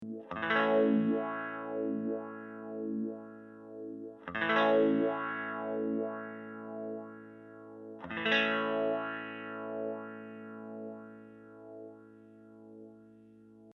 The sweep modes are ÒchoppyÓ and Òsmooth,Ó which refer to how quickly the sweep travels along the frequency range.
Sweeping the LF Setpoint control:  Clock Trigger, Smooth Mode